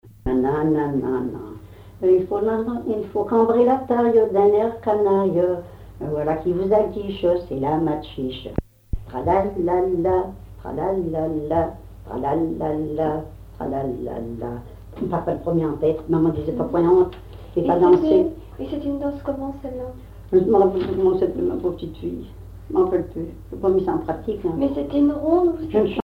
Localisation Cancale (Plus d'informations sur Wikipedia)
Fonction d'après l'analyste danse : matchiche (ou maxixe) ;
Genre brève
Catégorie Pièce musicale inédite